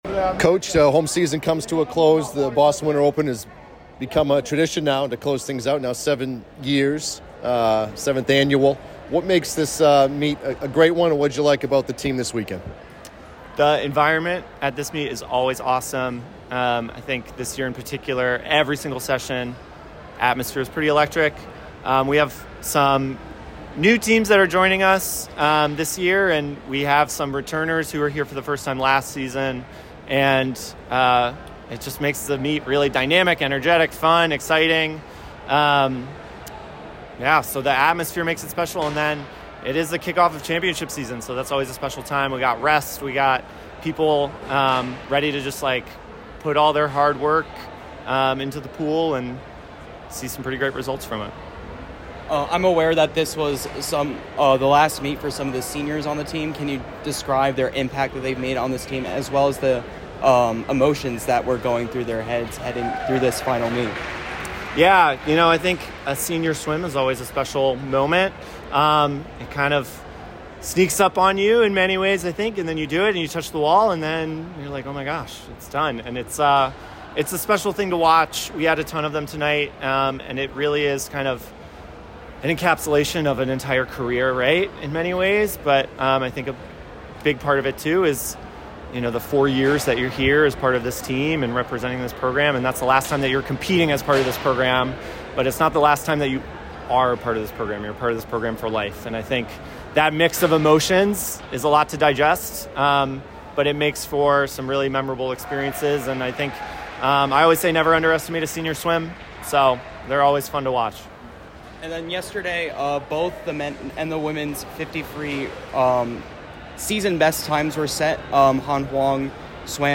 Boston Winter Open Postmeet Interview